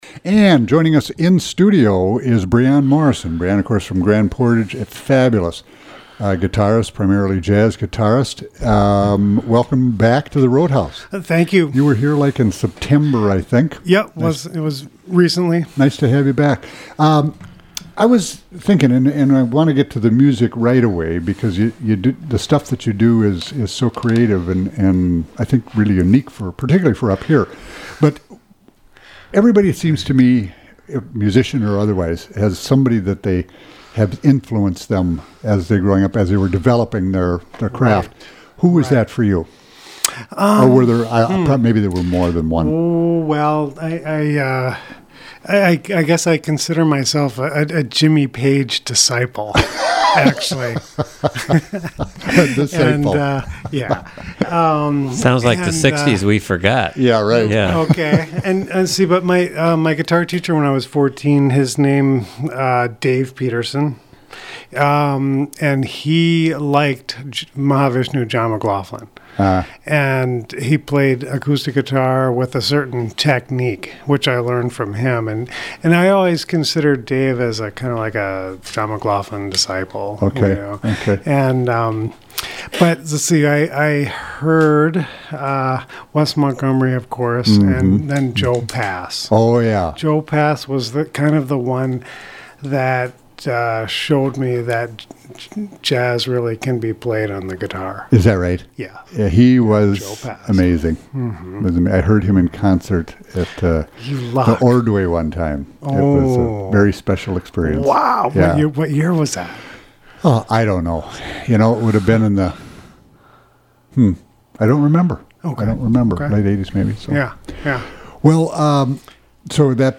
gorgeous jazz guitar